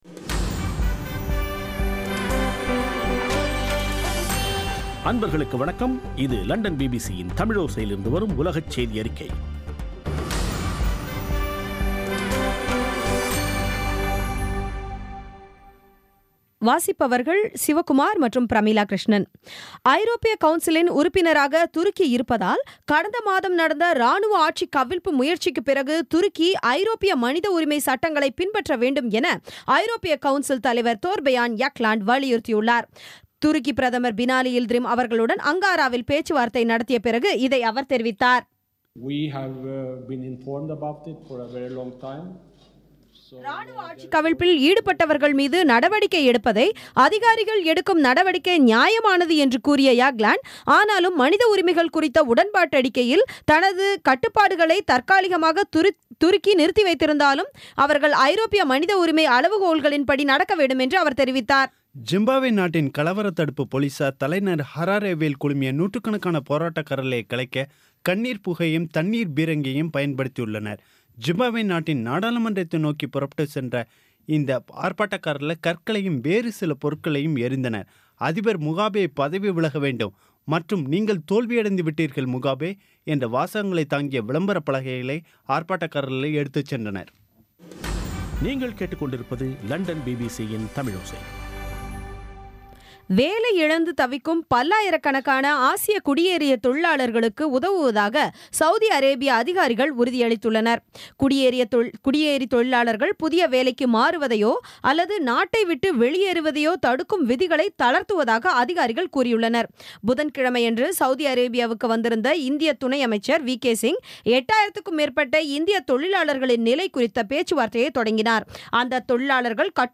பி பி சி தமிழோசை செய்தியறிக்கை (03/08/2016)